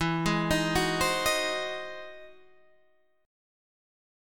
E+M9 Chord
Listen to E+M9 strummed